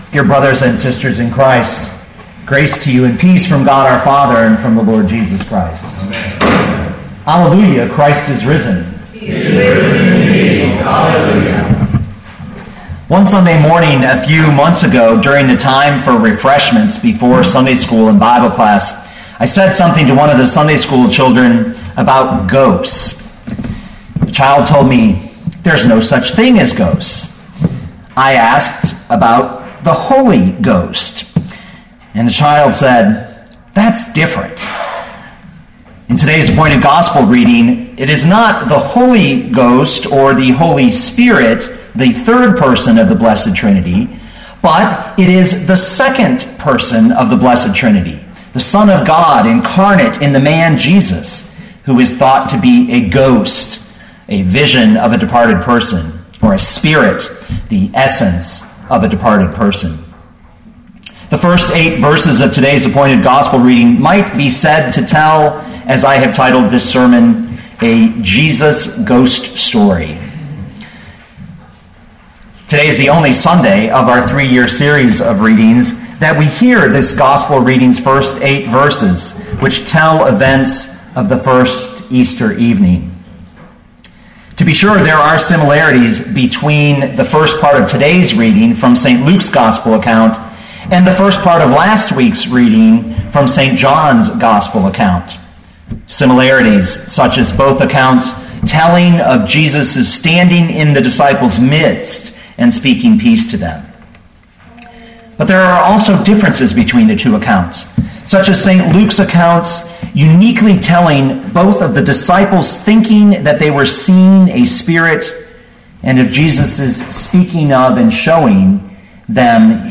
2015 Luke 24:36-43 Listen to the sermon with the player below, or, download the audio.